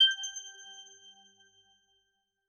Button_2_Pack2.wav